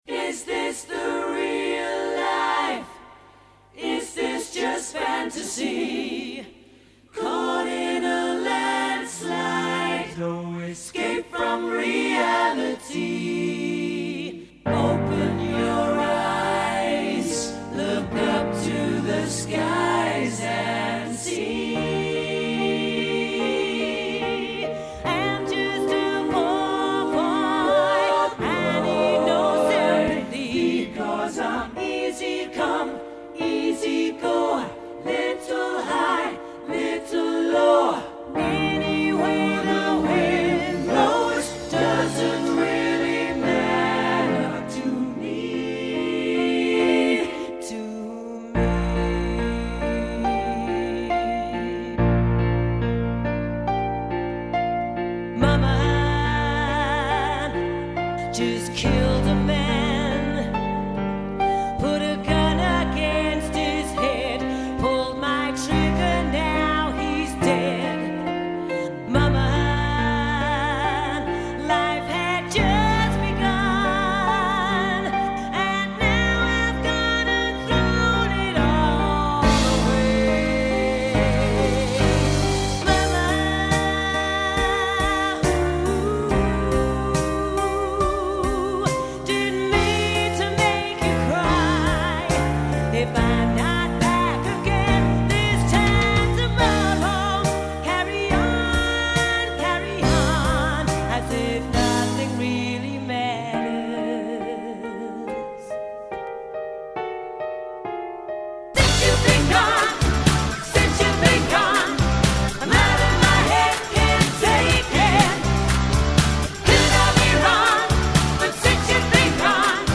Outstanding Vocals, Harmonies and musicianship
classic pop & soft rock tunes